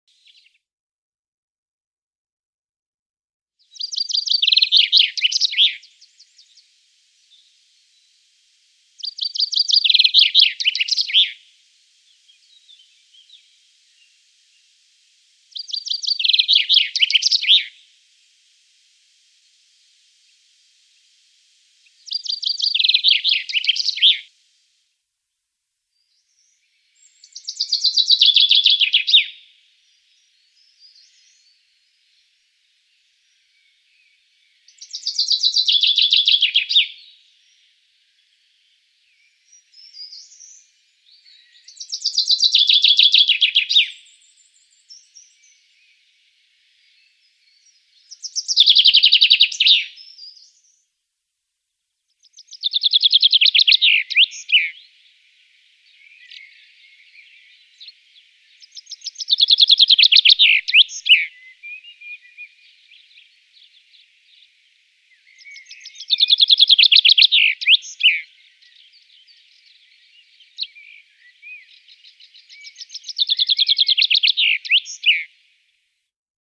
Buchfink mit Weibchen
Finken "schlagen" sehr vielseitig.